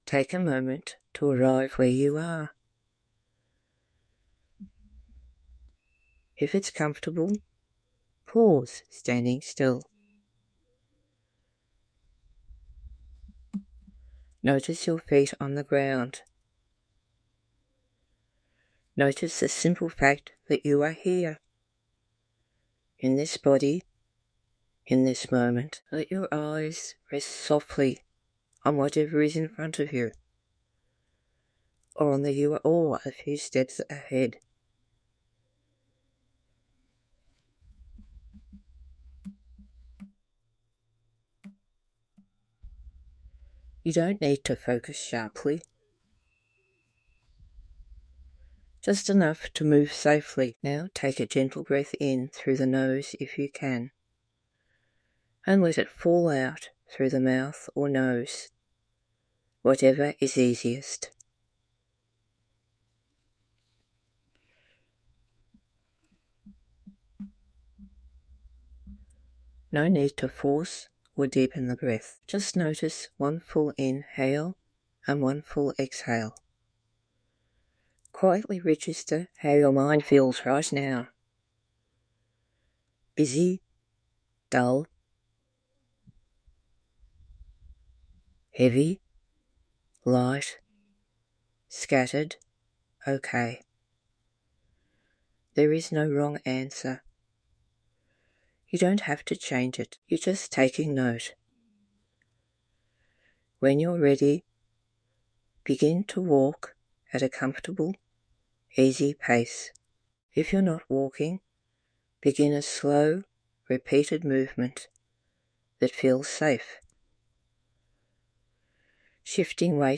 This gentle guided walk invites you to notice your surroundings, your body, and your breathing — without needing to change anything.